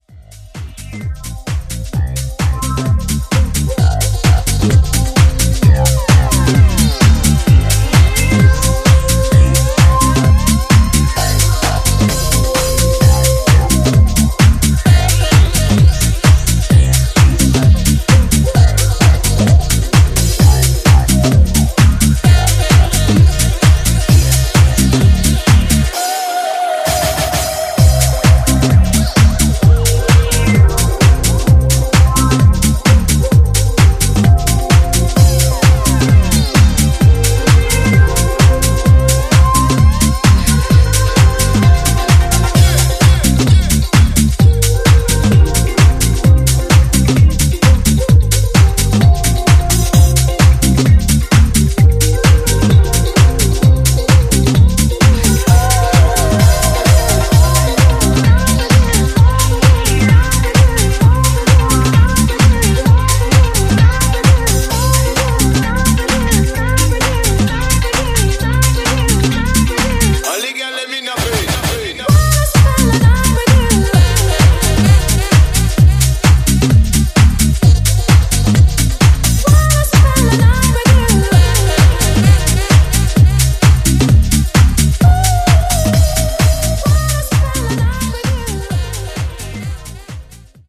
ジャンル(スタイル) HOUSE CLASSIC / UK GARAGE / DEEP HOUSE